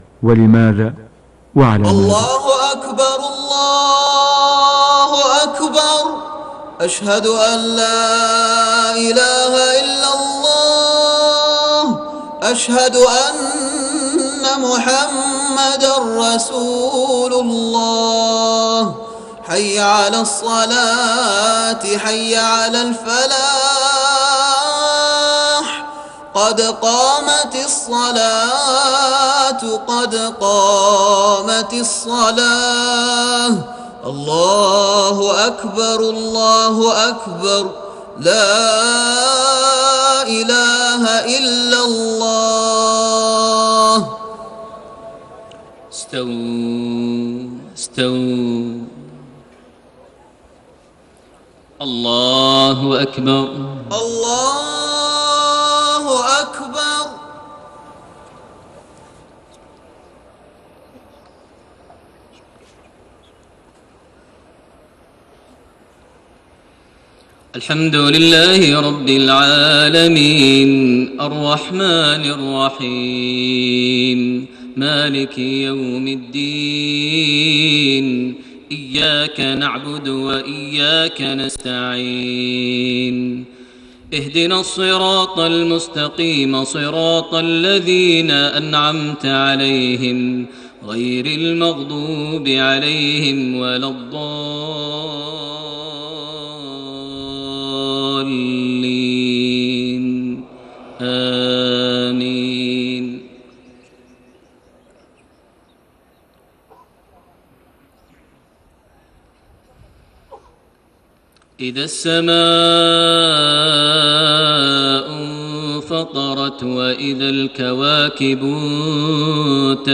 صلاة المغرب 12 ذو القعدة 1432هـ سورة الانفطار > 1432 هـ > الفروض - تلاوات ماهر المعيقلي